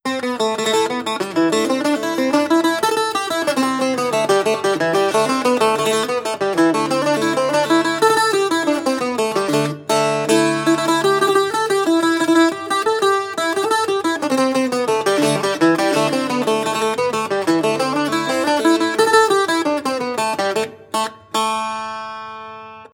• irish bouzouki folk melody.wav
irish_bouzouki_folk_melody_VpY.wav